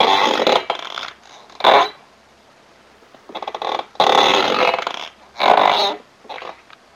椅子的挤压和呻吟声" 椅子的呻吟声
描述：一把挤压的旧办公椅，在旧货店买的。舒适，但声音很大。用索尼IC录音机录制，并对嘶嘶声进行过滤。一系列的呻吟和尖叫声。
Tag: 效果 呻吟 呻吟 LOFI squeek squeeky squeel